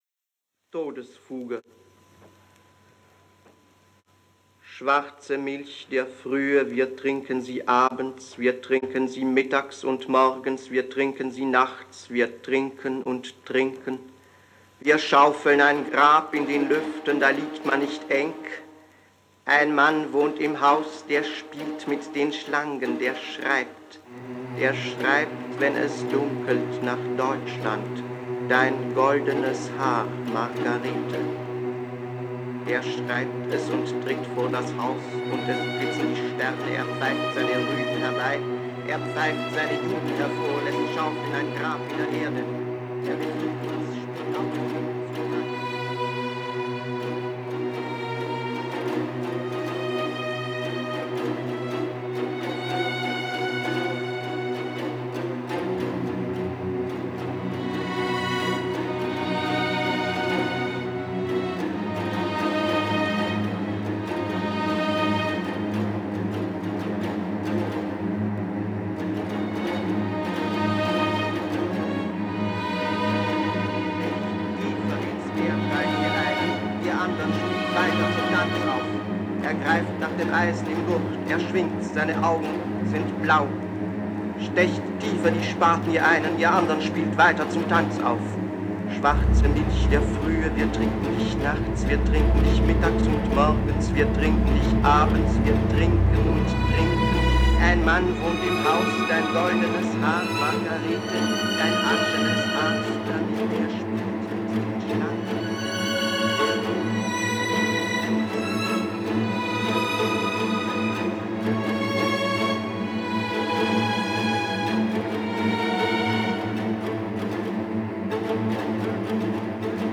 The composition is a MIDI orchestration, which I composed in MOTU’s Digital Performer, using an orchestral sample library of EastWest virtual instruments.
“In the Glittering Starlight” (revised instrumental with voice)
The ‘revised instrumental with voice’ audio file demonstrates how the viola line operates as a rhythmic translation of Paul Celan’s recorded oration of Todesfuge.
deathfugue_soundscapes_draftmix.wav